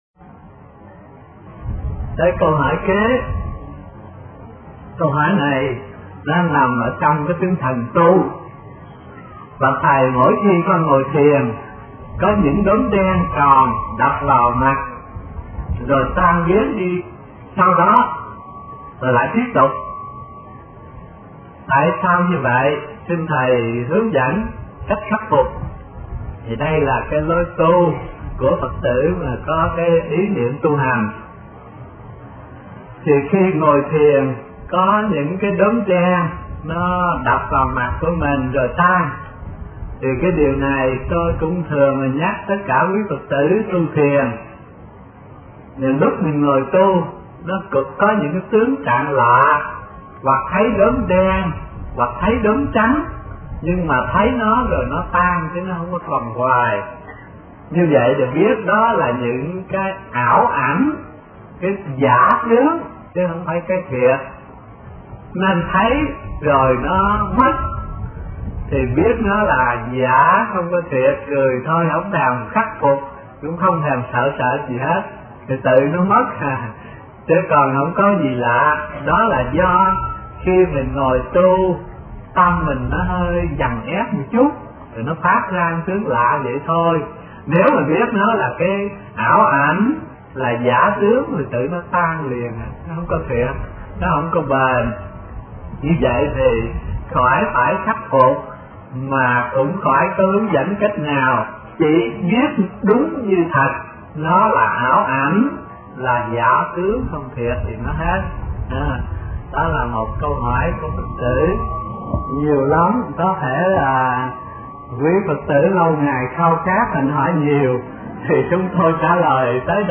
Ngồi Thiền Thấy Những Đốm Đen – Tham vấn HT Thích Thanh Từ mp3